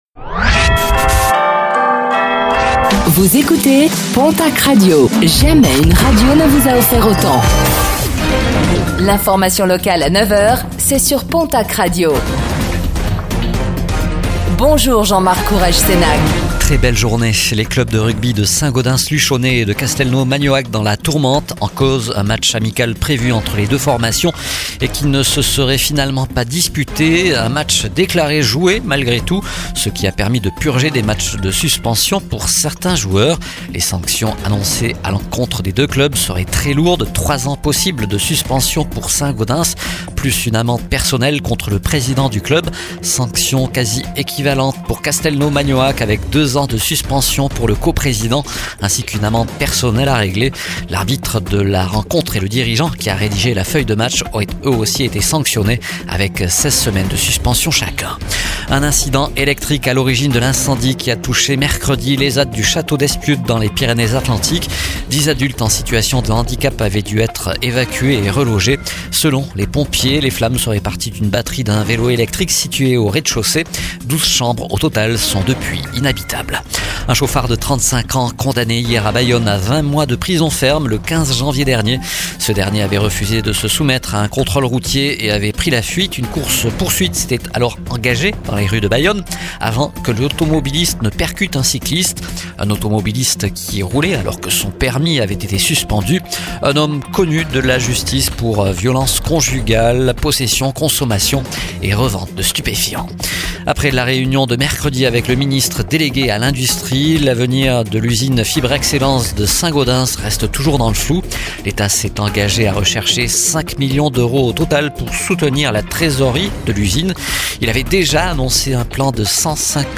Réécoutez le flash d'information locale de ce vendredi 13 mars 2026,